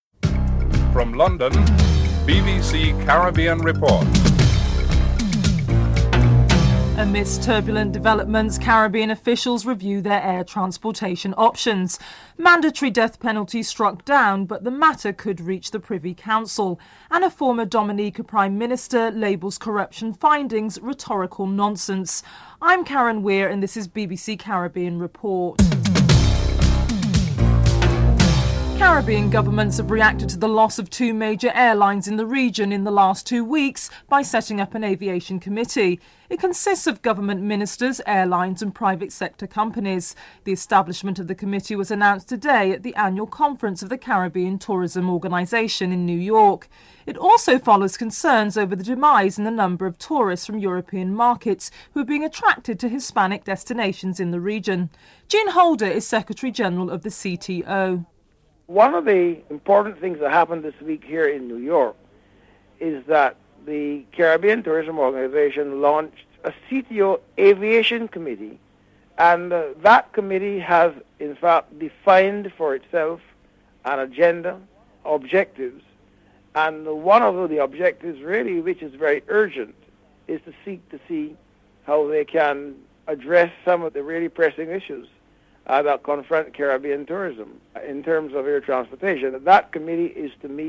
6. Former Dominican Prime Minister Edison James labels corruption findings rhetorical nonsense.